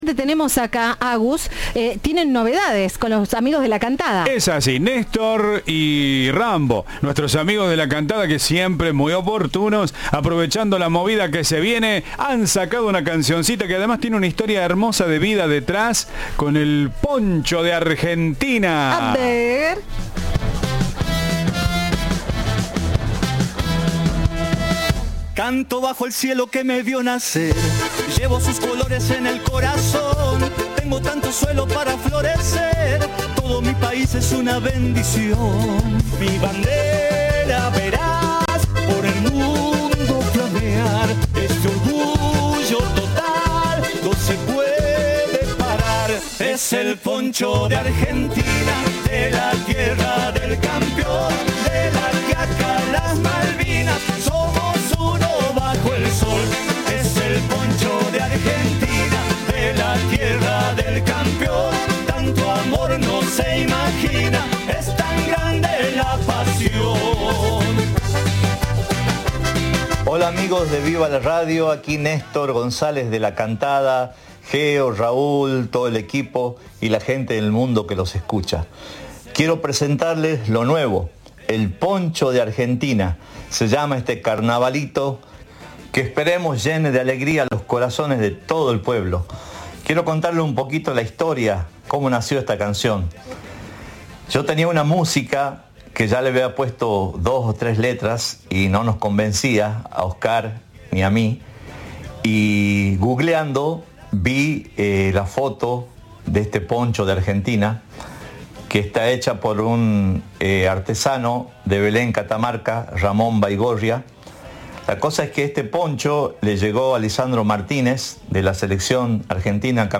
Entrevista de “Viva la Radio”